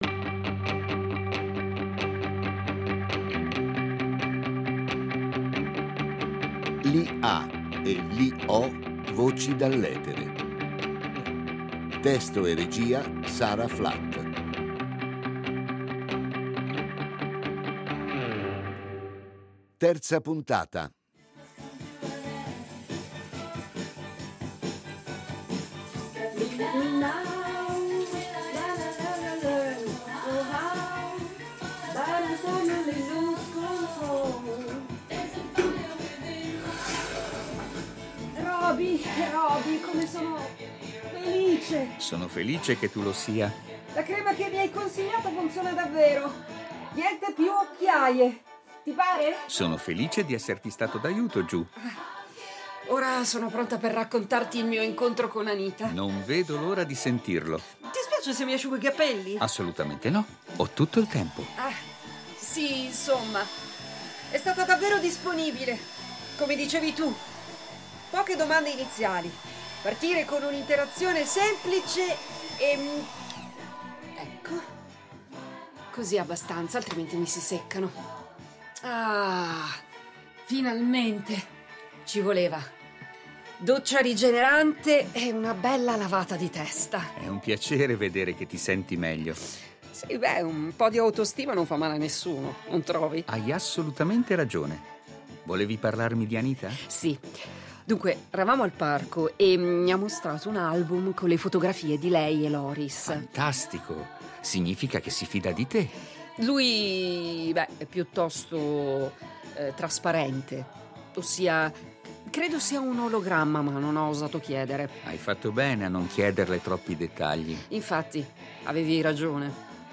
Robi, un’IA evoluta, impersonata dalla voce più che reale